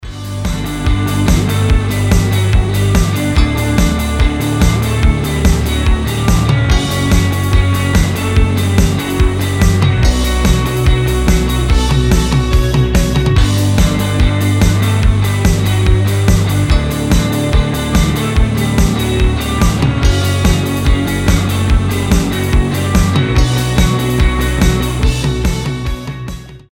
• Качество: 320, Stereo
без слов
alternative
пост-панк
Меланхоличная альтернативная музыка